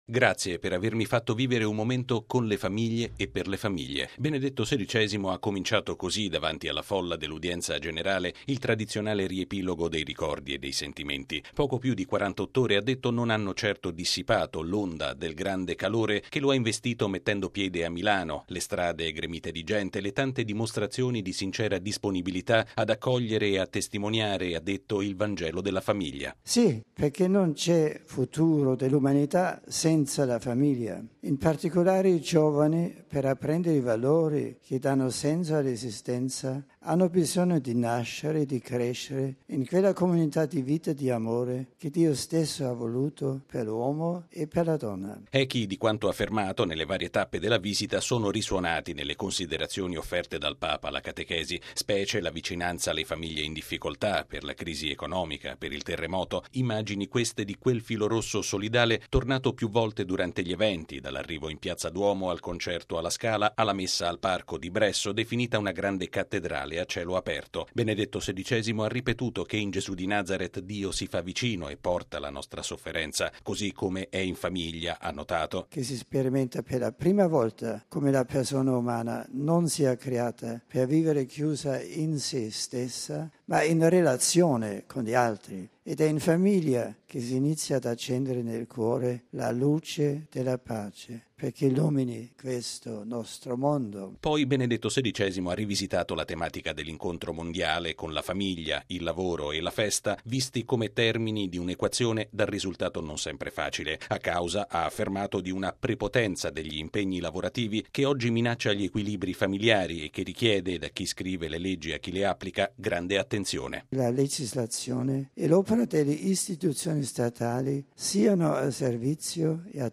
Reduce dai giorni del settimo Incontro mondiale delle famiglie, Benedetto XVI ha terminato con questa affermazione l’udienza generale di stamattina in Piazza San Pietro, dove ha ricordato le esperienze vissute lo scorso fine settimana nel capoluogo lombardo.